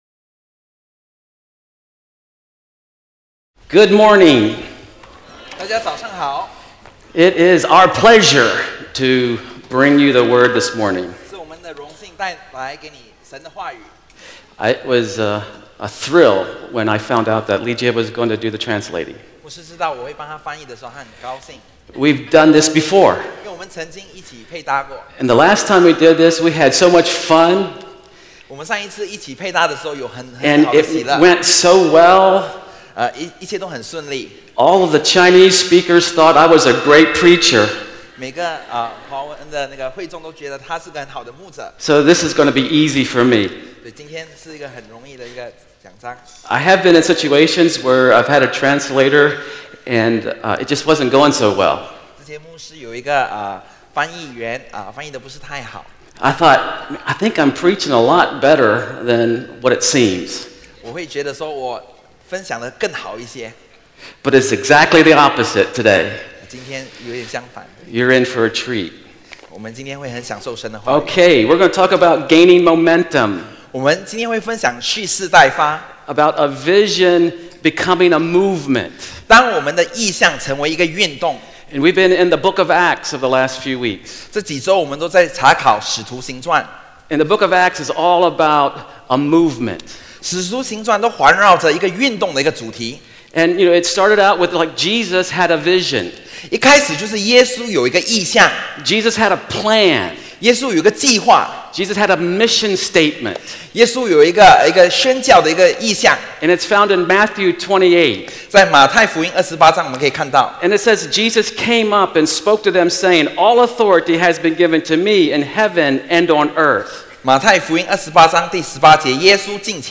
主日证道 |  Gaining Momentum